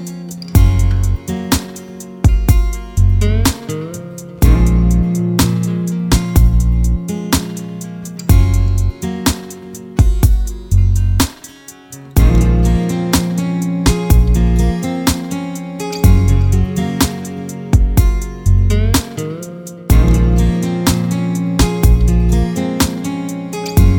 no Backing Vocals R'n'B / Hip Hop 4:14 Buy £1.50